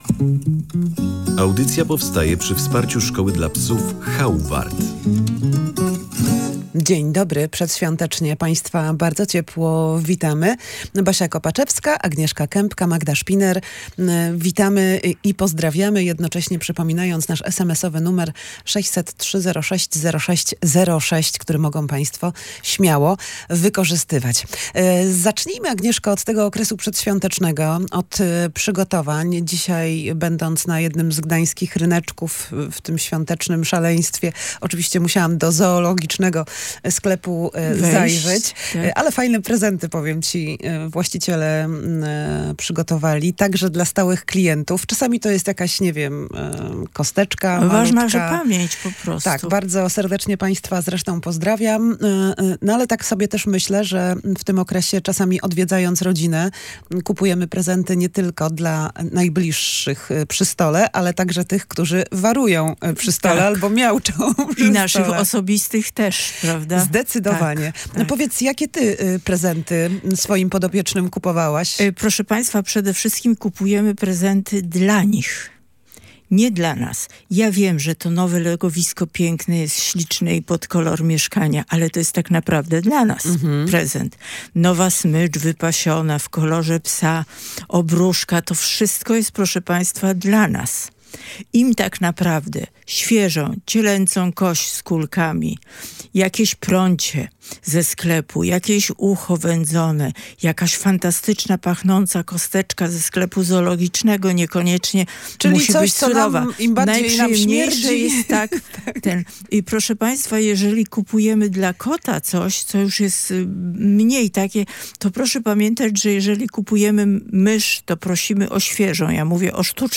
w rozmowie z prowadzącą audycję